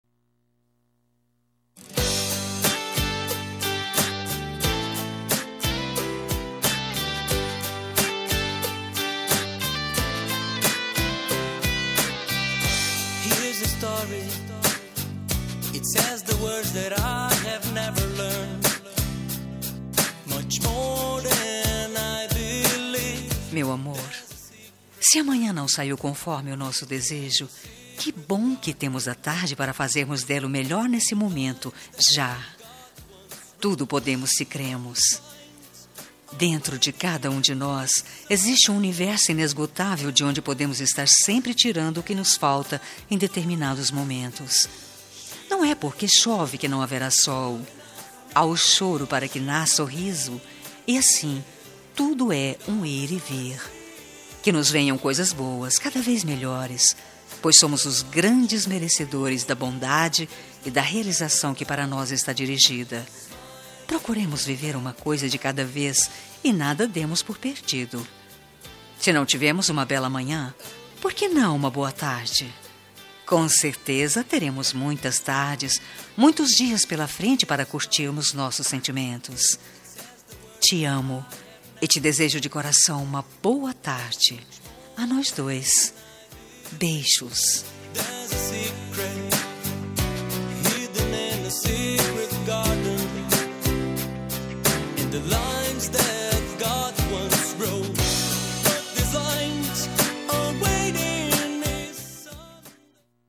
Telemensagem de Boa Tarde – Voz Feminina – Cód: 6317 – Romântica